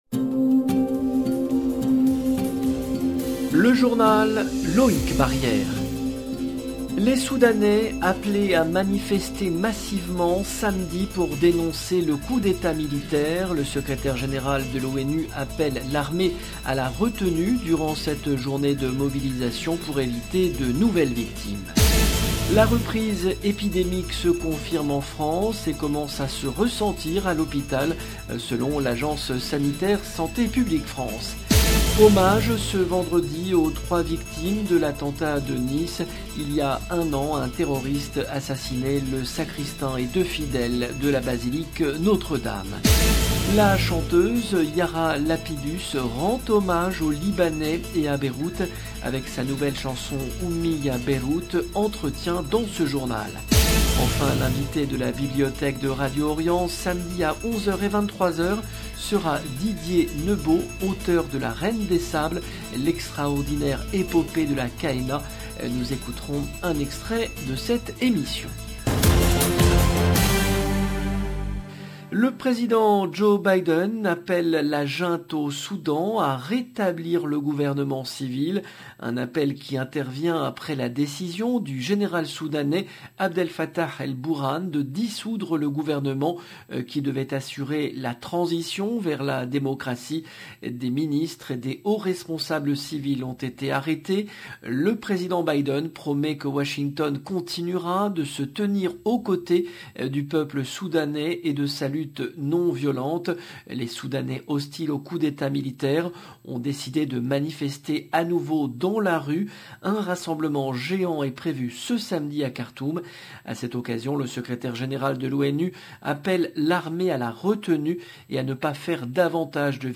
Entretien dans ce journal.